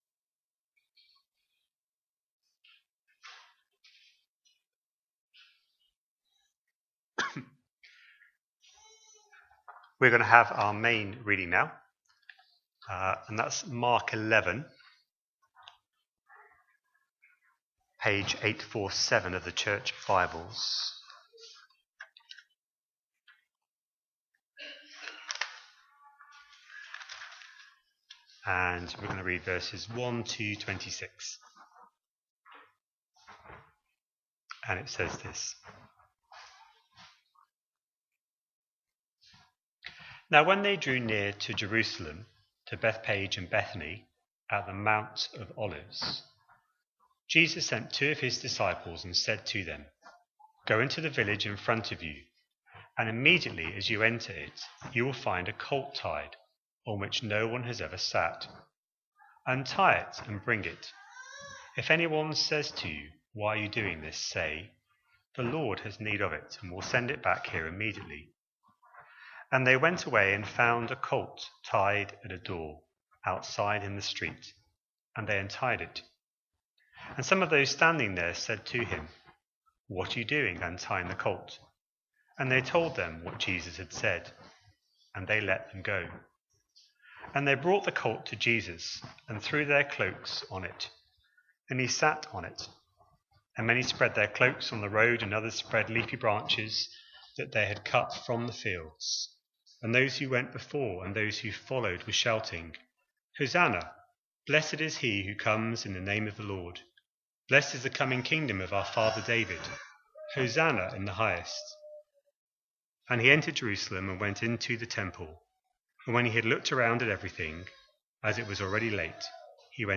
A sermon preached on 25th January, 2026, as part of our Mark 25/26 series.